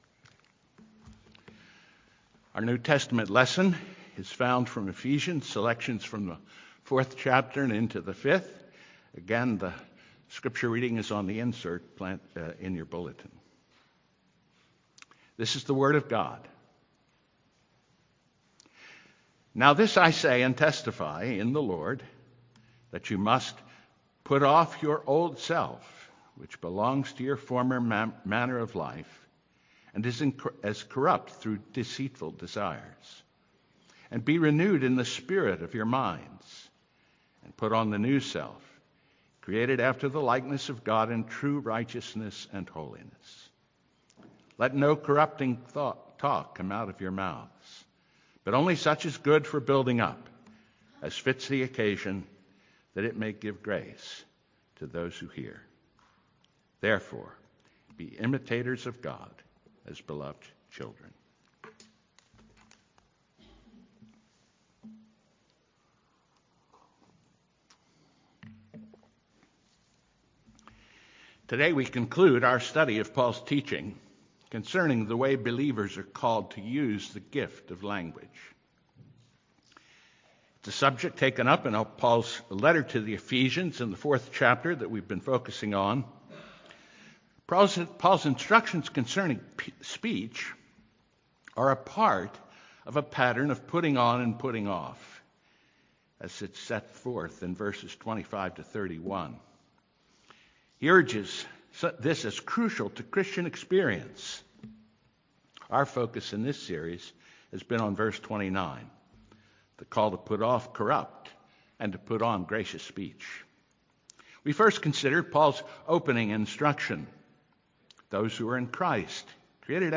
The Fourfold Calling of Gracious Speech, Part 4 - Fitting Speech: Sermon on Ephesians 4:29 - New Hope Presbyterian Church
november-2-2025-sermon-only.mp3